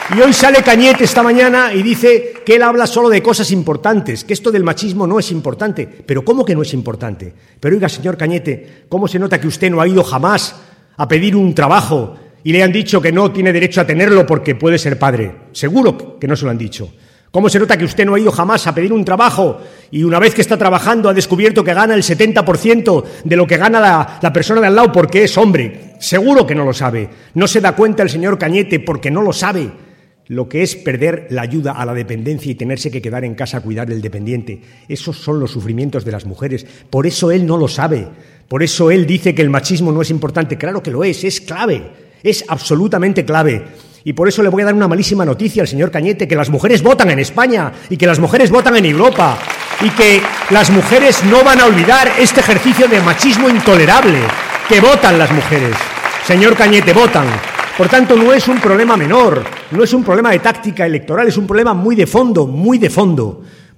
En el Teatro Victoria, de Talavera de la Reina, donde 600 militantes y simpatizantes socialistas han acompañado a Rubalcaba, y donde han intervenido además el líder de los socialistas castellano-manchegos, Emiliano García Page, y el candidato al Parlamento Europeo Sergio Gutiérrez, el Secretario General del PSOE ha recordado además que Cospedal se estrenó como presidenta de Castilla-La Mancha quitando las ayudas a las mujeres víctimas de la violencia de género, “precisamente las mujeres que más ayuda necesitan”.